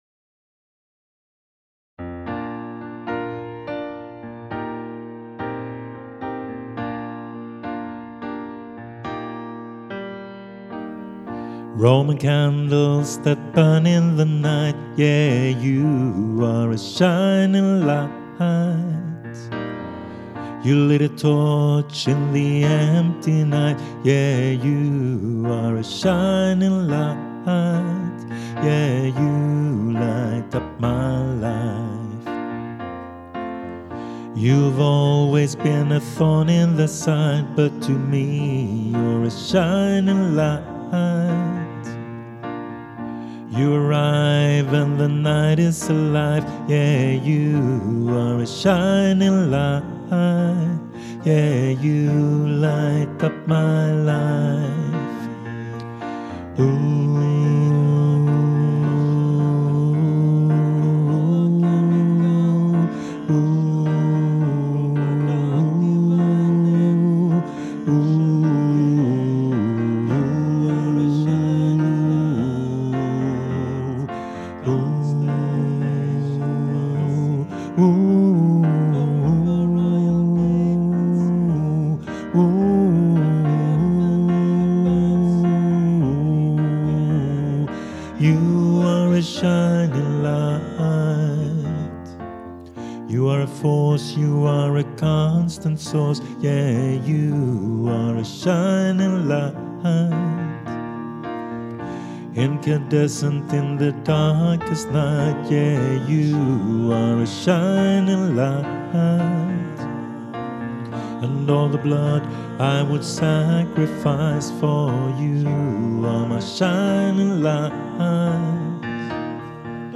Shining Light 2022 - alt 2 (damtenor).mp3